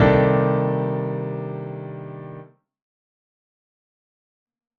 So play a C-7 chord to begin.
C-9-13-1.wav